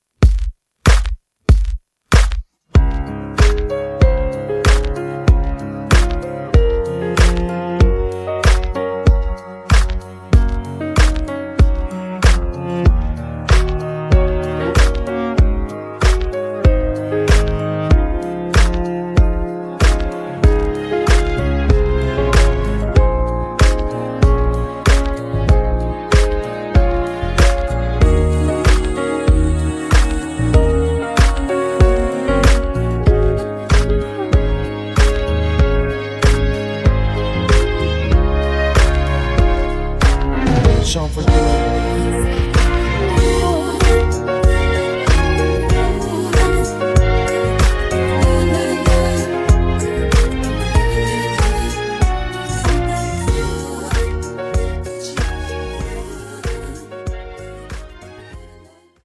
음정 -1키 3:51
장르 가요 구분 Voice MR